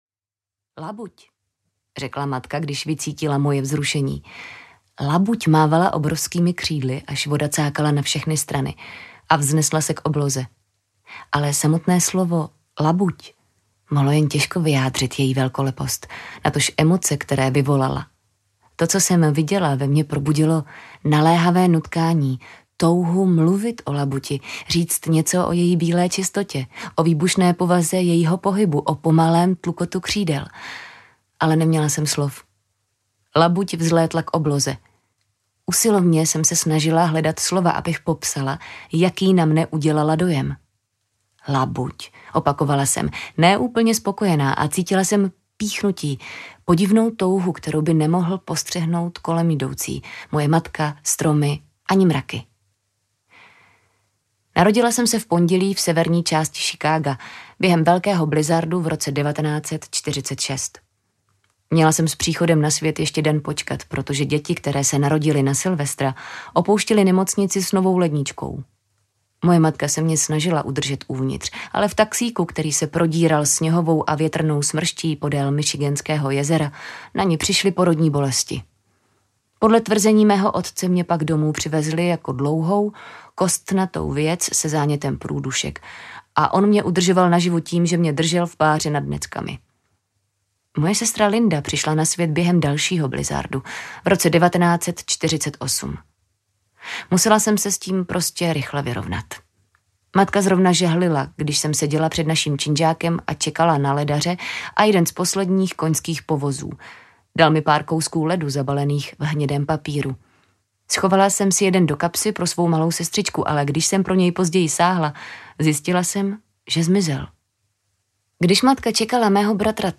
Audio knihaPatti Smith: Just kids – Jsou to jen děti
Ukázka z knihy